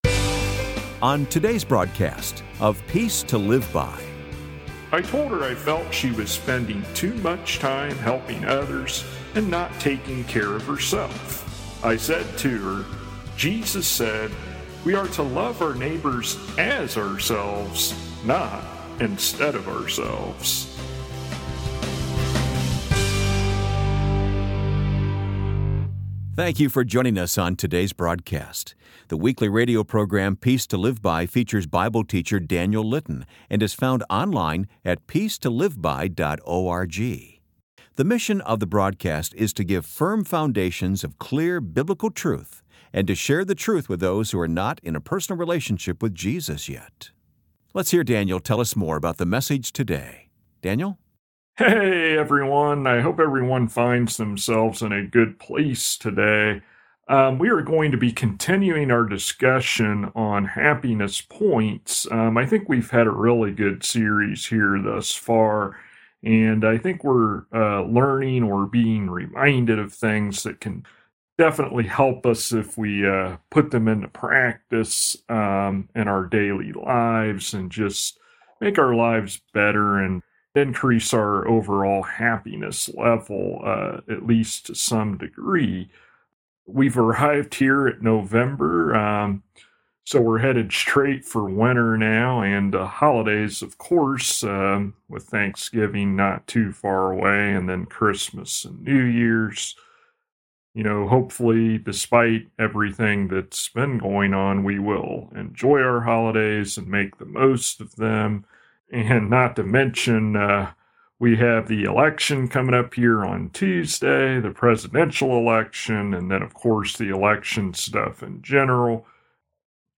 [Transcript may not match broadcasted sermon word for word] Happiness Point #22: God Isn’t Limited on Resources